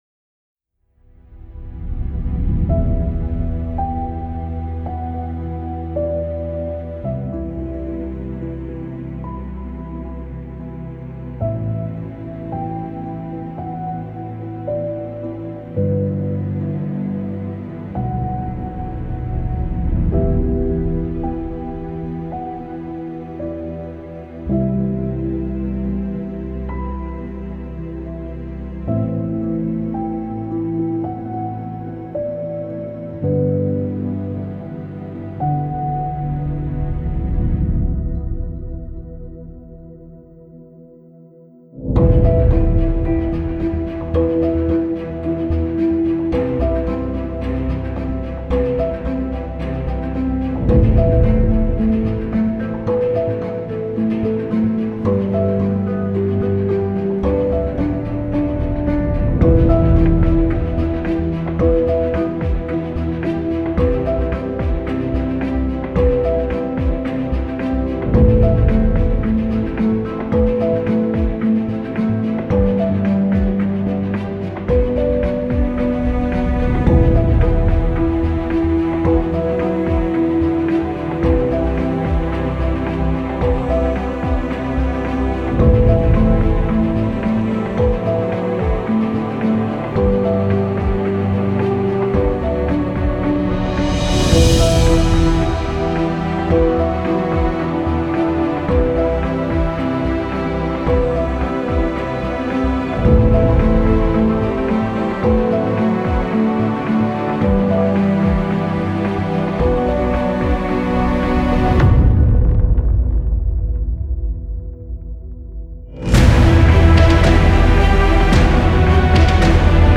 Genres: Epic